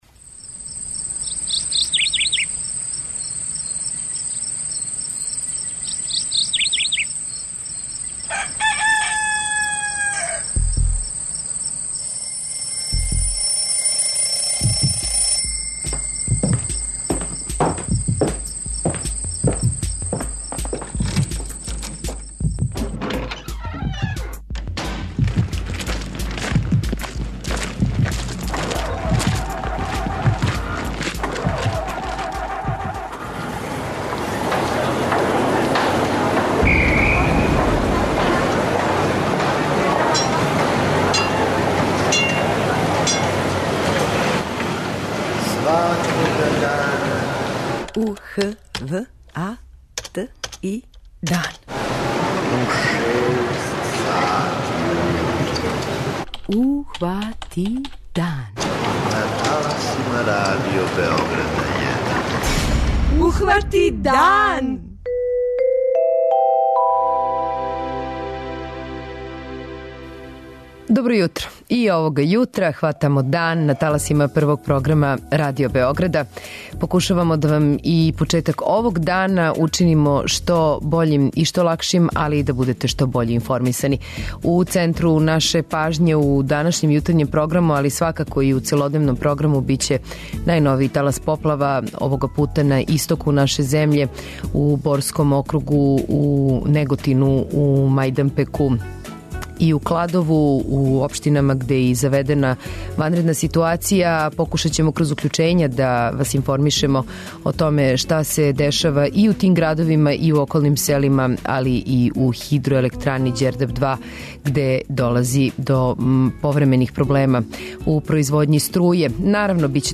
Кроз директна укључења саговорника из Кладова, Неготина и из Хидроелектране Ђердап, пратићемо каква је ситуација у подручјима угроженим најновијим поплавама.